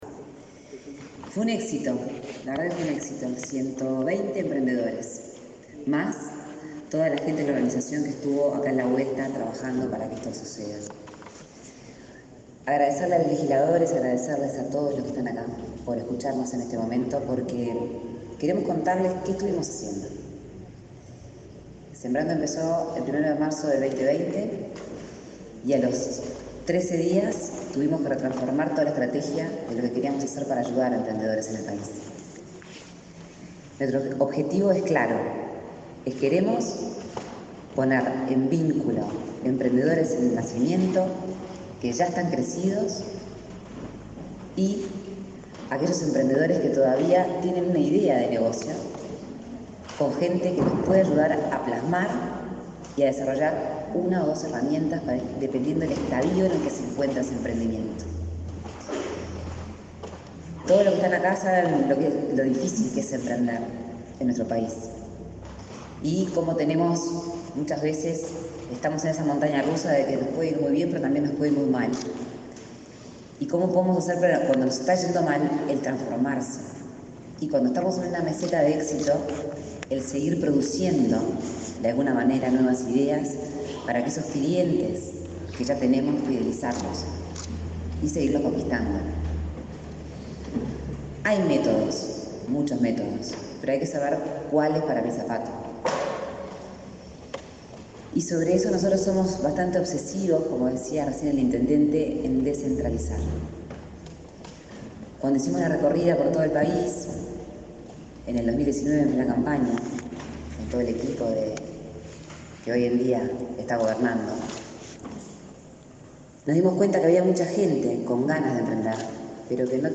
Conferencia de la impulsora del programa Sembrando, Lorena Ponce de León
Conferencia de la impulsora del programa Sembrando, Lorena Ponce de León 26/05/2022 Compartir Facebook X Copiar enlace WhatsApp LinkedIn La impulsora del programa Sembrando, Lorena Ponce de León, brindó una conferencia en Cerro Largo, luego de realizar varias actividades en ese departamento, vinculado al fomento de la cultura emprendedora.